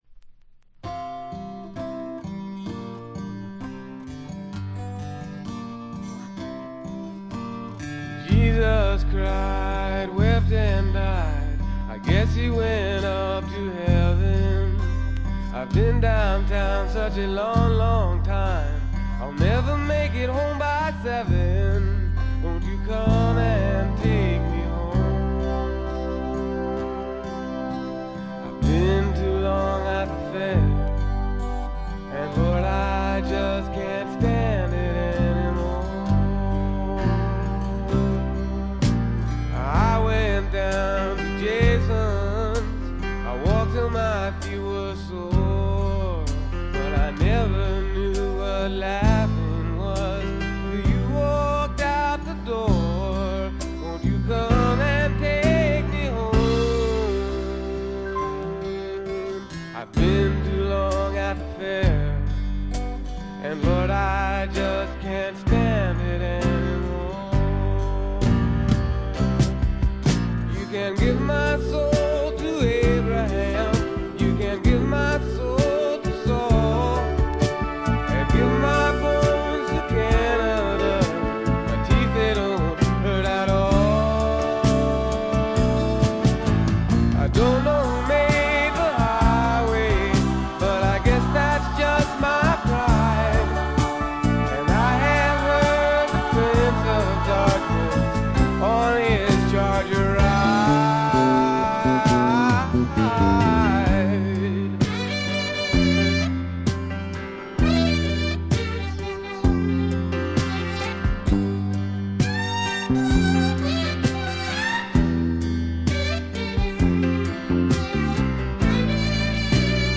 Recorded At - Bearsville Studios
Recorded At - Secret Sounds Studios N.Y.C.